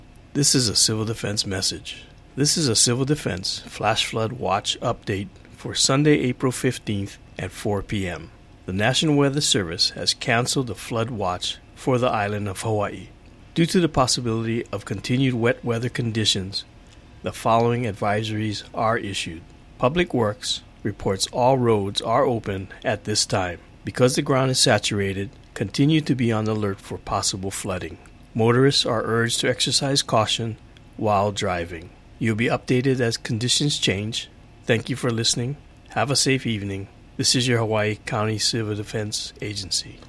Hawaii County Civil Defense 4 p.m. audio message